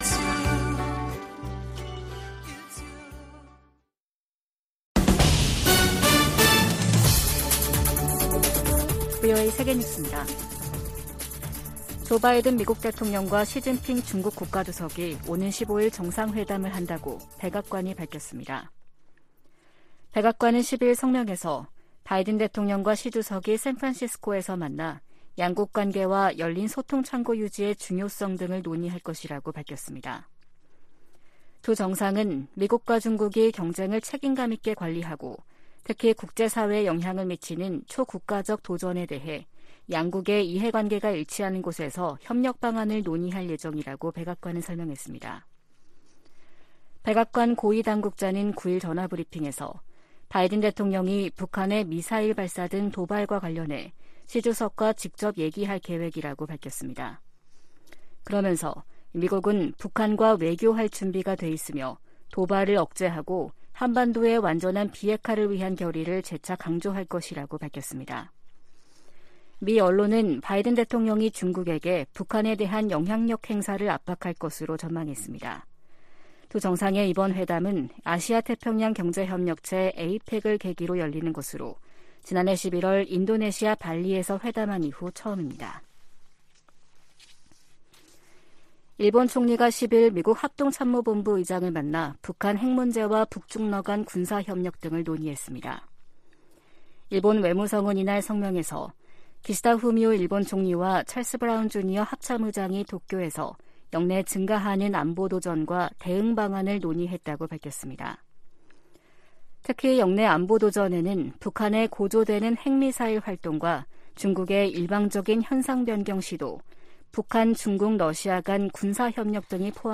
VOA 한국어 아침 뉴스 프로그램 '워싱턴 뉴스 광장' 2023년 11월 12일 방송입니다. 토니 블링컨 미국 국무장관이 윤석열 한국 대통령과 만나 북한과 우크라이나, 가자지구 문제 등 양국 현안을 논의했다고 국무부가 밝혔습니다. 미국 정부가 북한을 비호하는 러시아의 태도를 비판하면서 북한 정권에 분명한 메시지를 전할 것을 촉구했습니다.